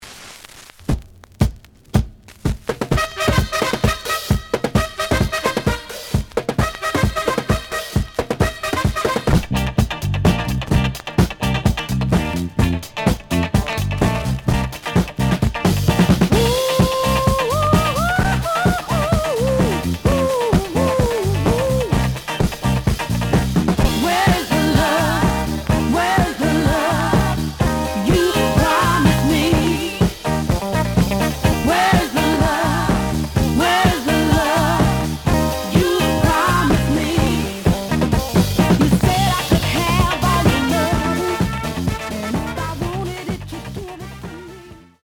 The audio sample is recorded from the actual item.
●Genre: Soul, 70's Soul
Some noise on beginning of A side.)